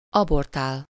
Ääntäminen
IPA: [a.vɔʁ.te]